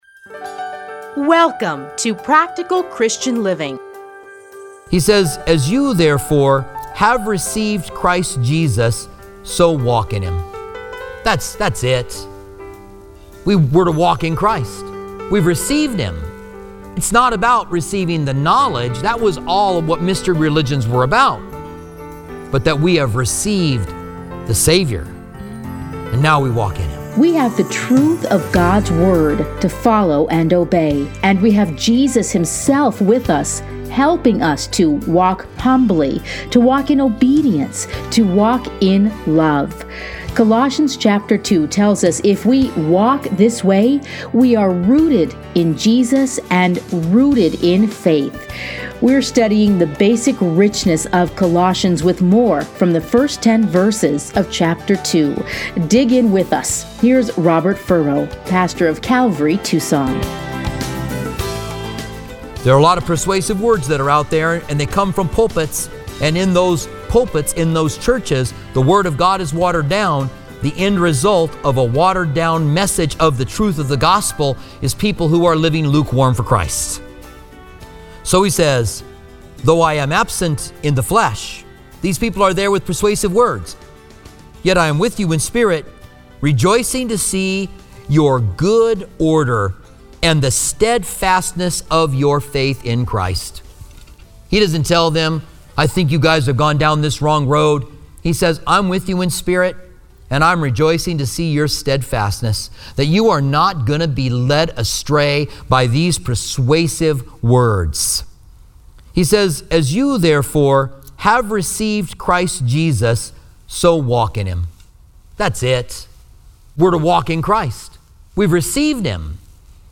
Listen here to a teaching from Colossians.